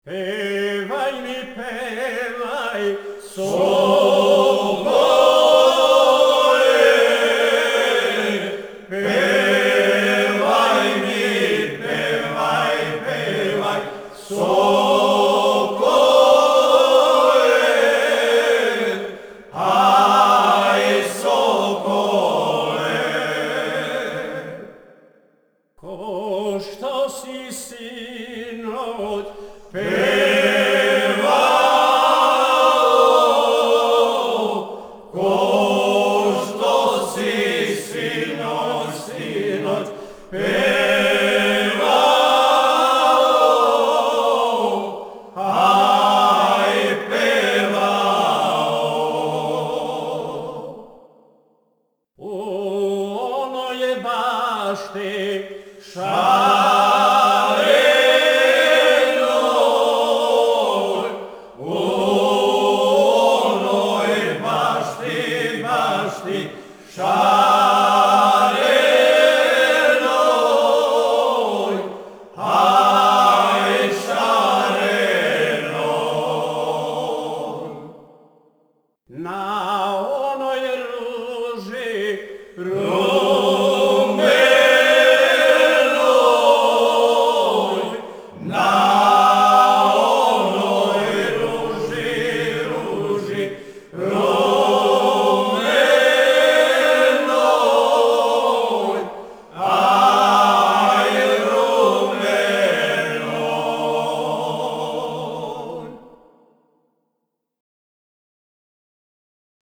Снимци Група "Стеван Книћанин", Кнић (3.3 MB, mp3) О извођачу Певај ми певај соколе ај соколе Ко што си синоћ певао ај певао У оној башти шареној ај шареној На оној ружи руменој ај руменој Порекло песме: Шумадија Начин певања: ?